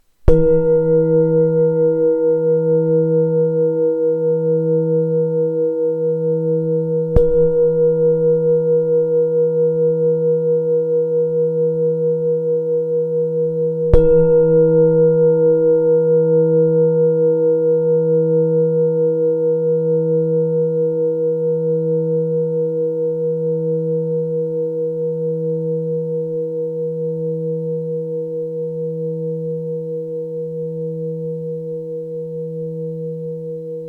Lingam tibetská mísa E3 26,5cm
Nahrávka mísy úderovou paličkou:
Každopádně jsou to mísy, které zní hlouběji a jsou opravdu krásné.
Její tajemný zvuk vás vtáhne do meditačního světa plného klidu a mystiky.
Tahle krásně zdobená mísa zní v tónu čakry solar plexus.